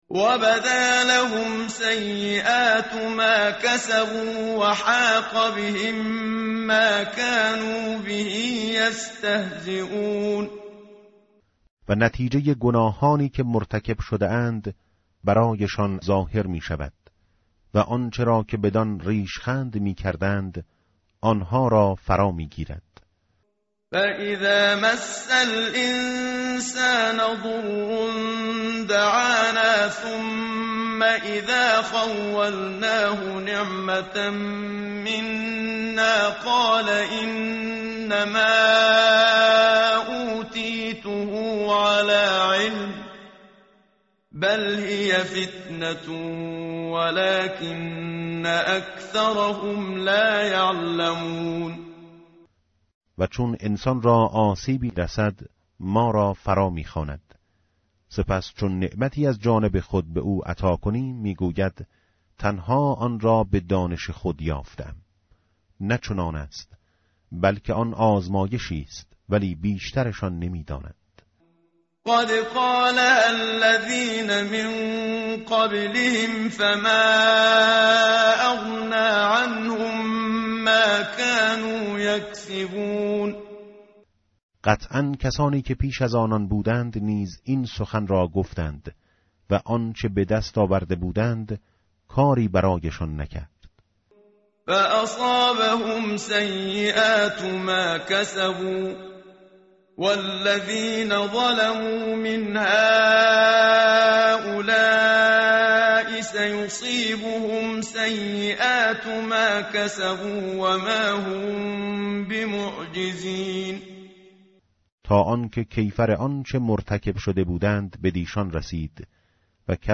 tartil_menshavi va tarjome_Page_464.mp3